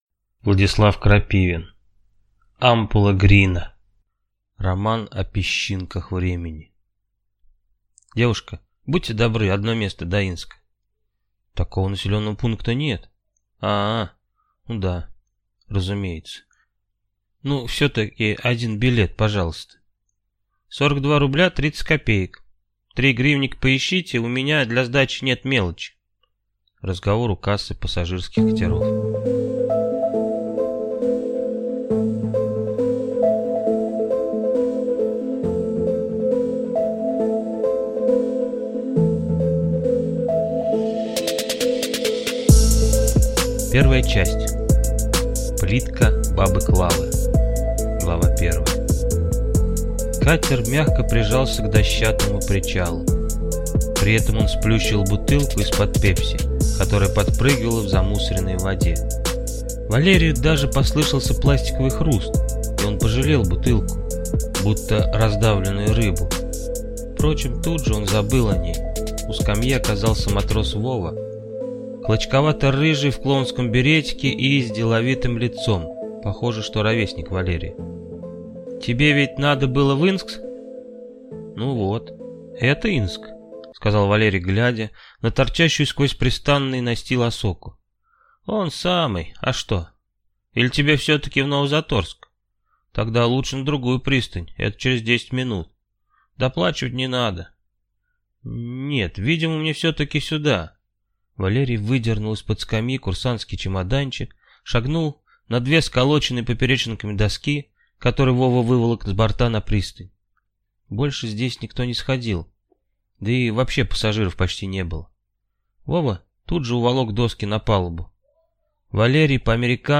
Аудиокнига Ампула Грина | Библиотека аудиокниг
Прослушать и бесплатно скачать фрагмент аудиокниги